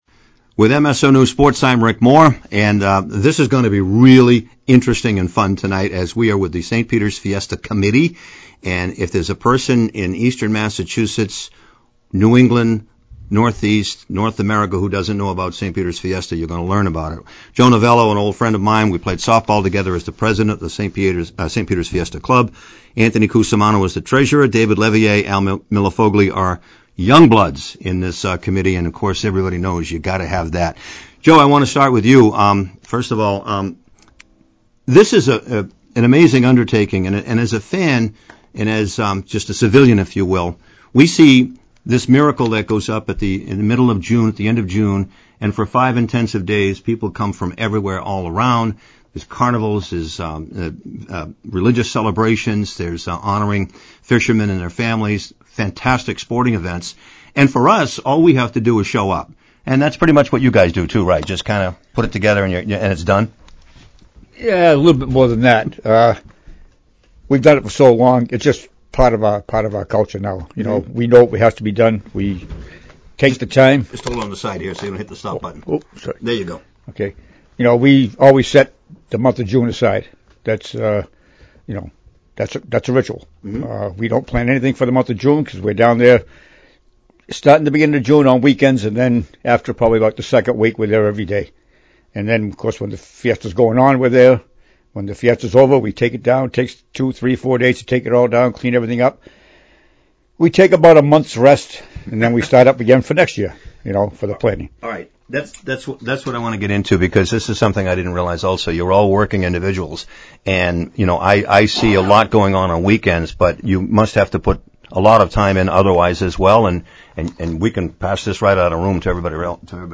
(Updated Audio, Photos) If It’s June, It Must Be St. Peter’s Fiesta Time in Gloucester – Behind the Scenes with the Fiesta Committee -Complete Audio Interview Below